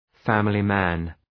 Leave a reply family man Dëgjoni shqiptimin https